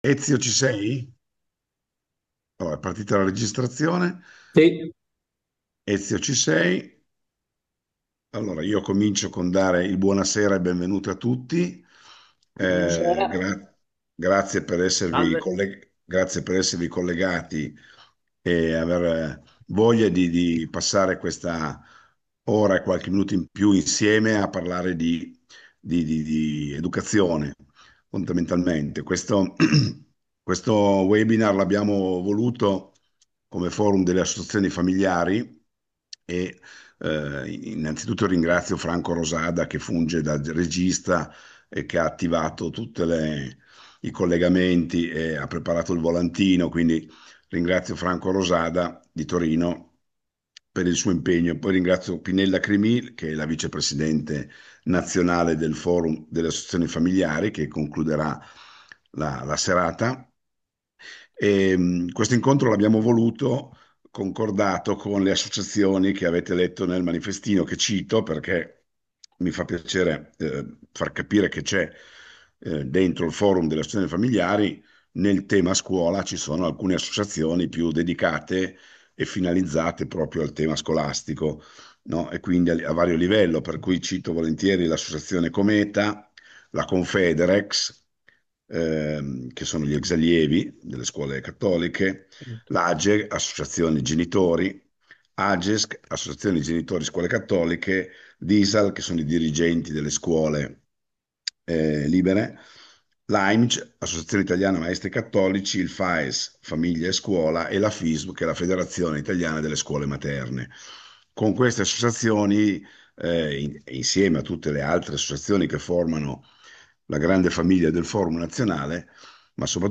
Disegnare nuove mappe di speranza Incontro formativo on-line sulla lettera apostolica di Papa Leone XIV in occasione del LX anniversario dalla dichiarazione conciliare Gravissimus educationis.